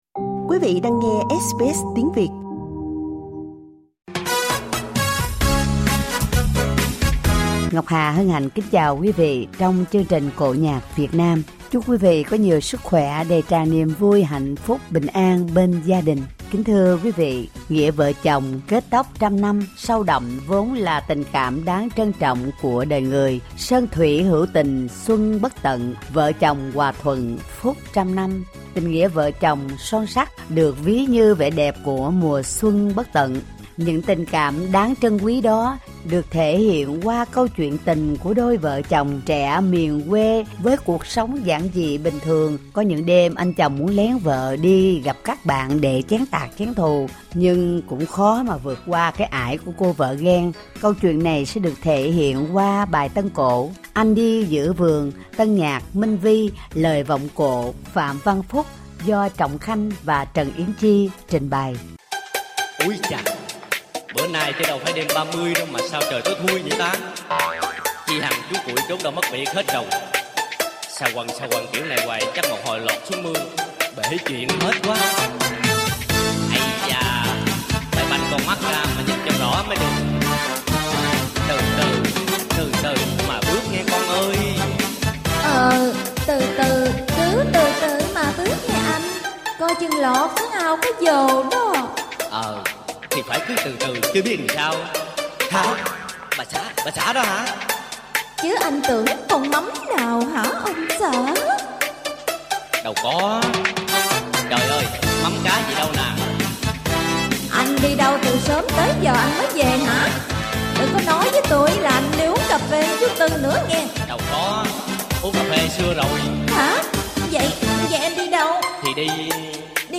Vọng cổ